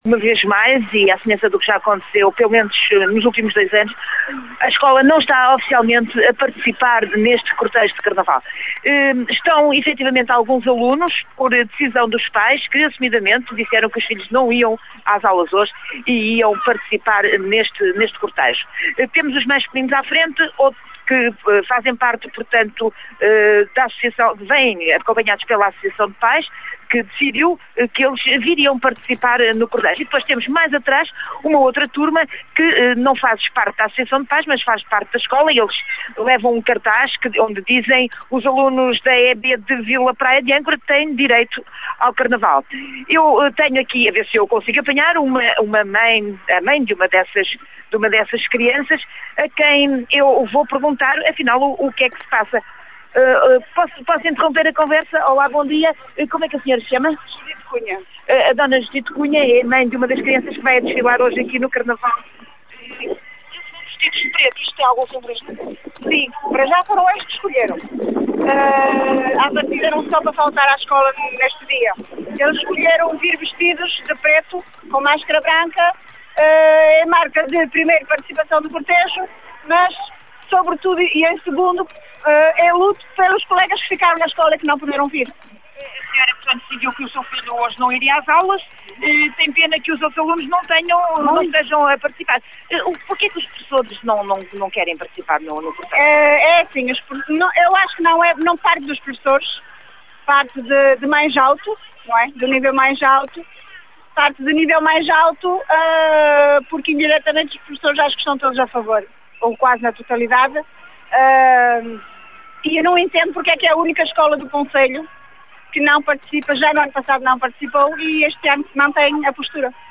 que está a assistir ao corso carnavalesco nas ruas de Vila Praia de Âncora